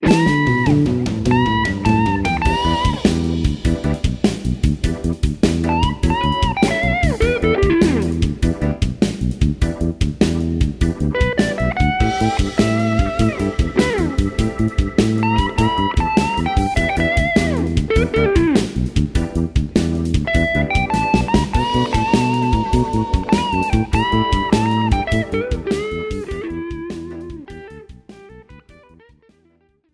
Without Backing Vocals. Professional Karaoke Backing Track.